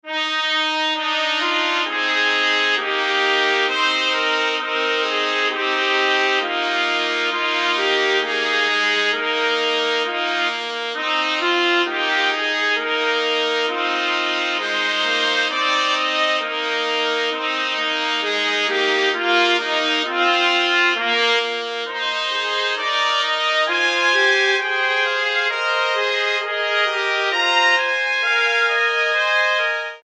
Arrangement for three trumpets
Description:Classical; band music
Instrumentation:tr.1, tr.2, tr.3